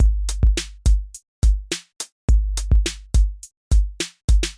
标签： 105 bpm Electro Loops Drum Loops 787.67 KB wav Key : Unknown
声道立体声